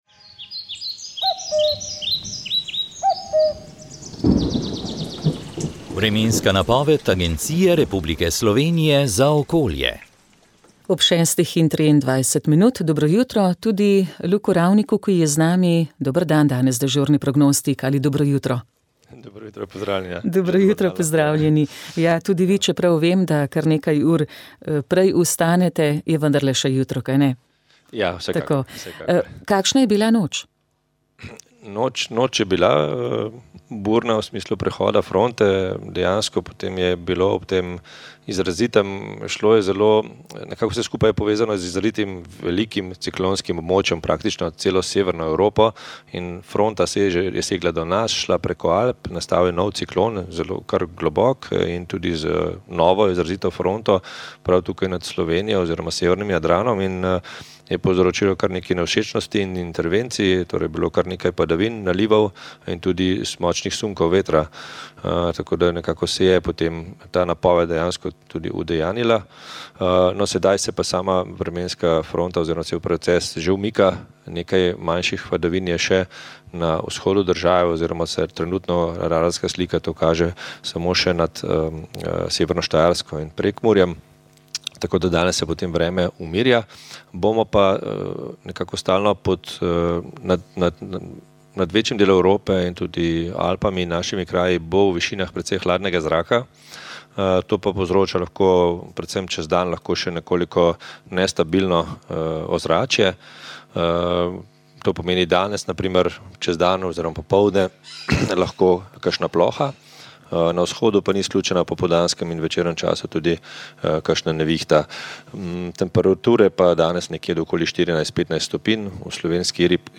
Vremenska napoved 10. september 2024